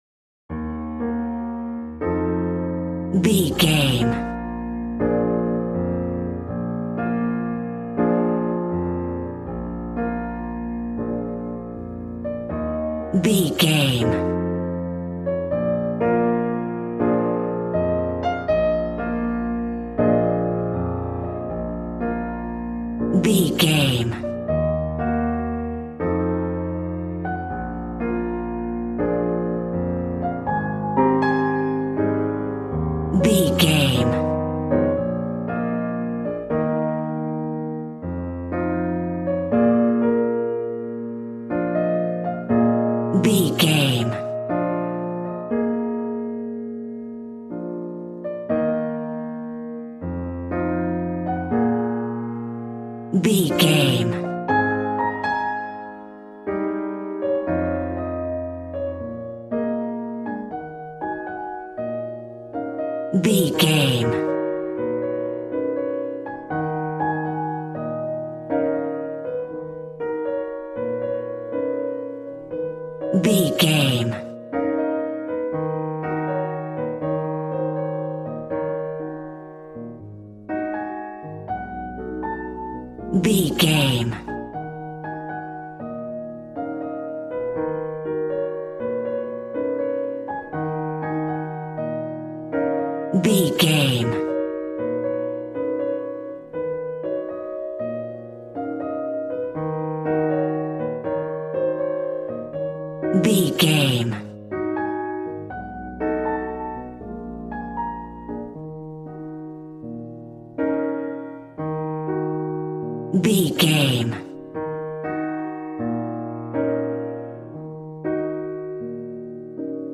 Smooth jazz piano mixed with jazz bass and cool jazz drums.,
Ionian/Major
B♭
piano
drums